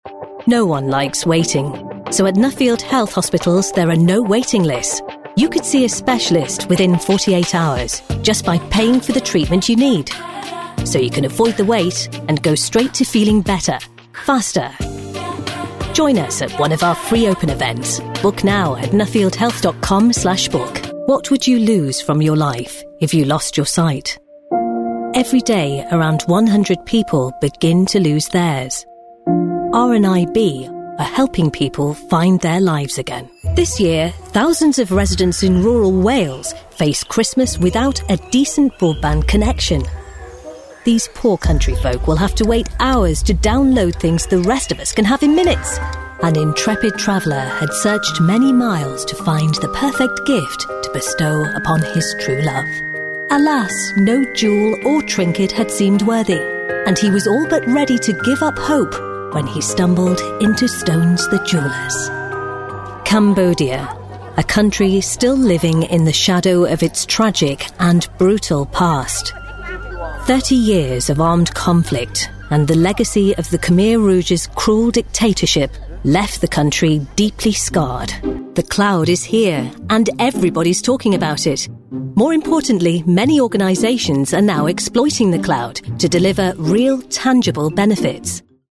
Female / 40s / English / Southern
Showreel